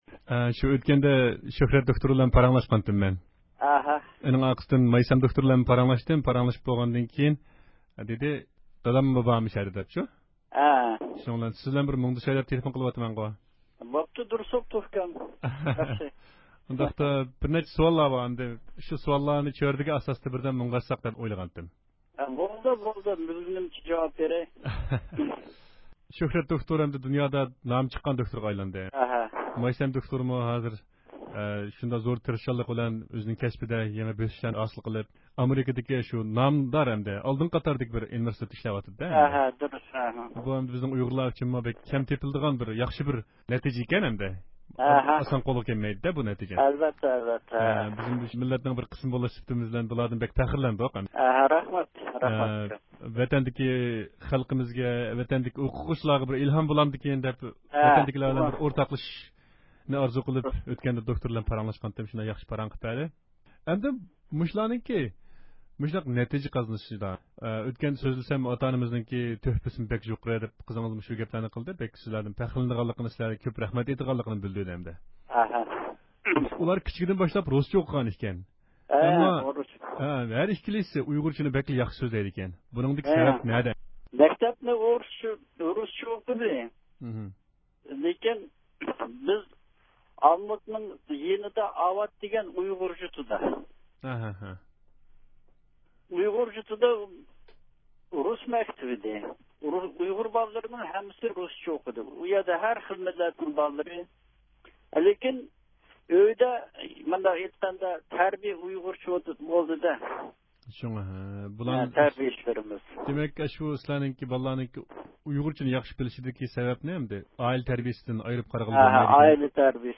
ئاكا-سىڭىل ئۇيغۇر ئالىملارنىڭ دادىسى بىلەن سۆھبەت – ئۇيغۇر مىللى ھەركىتى